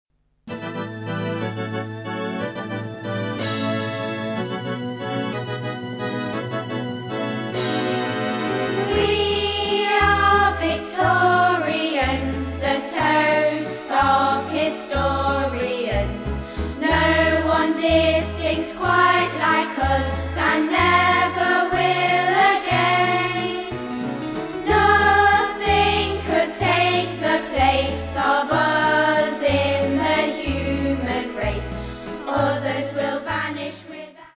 Sample from the Backing CD